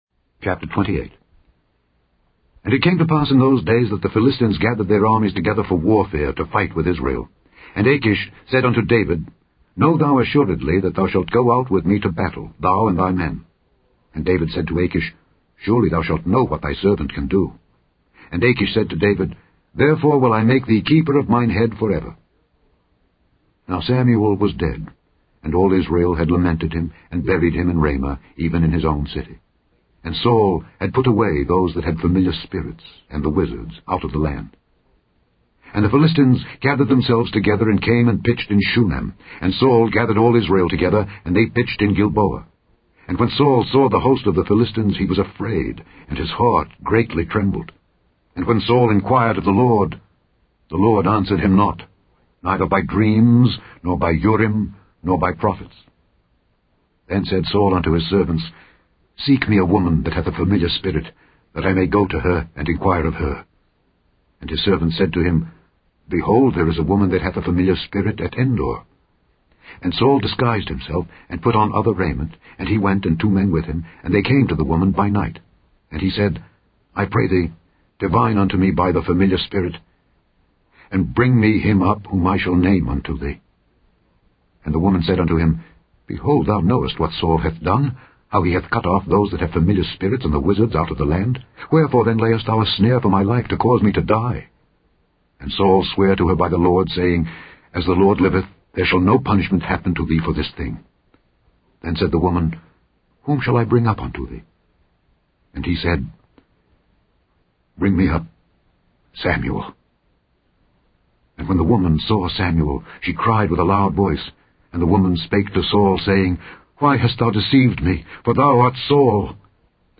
(Hebrews 2:1-3) * * * * * * * * * * * * * * * * * * * This Week's Daily Bible Reading * * * * * * * * * * * * * * * * * * * The Scourby Bible Readings are being aired with the permission of copyright owner Litchfield Associates.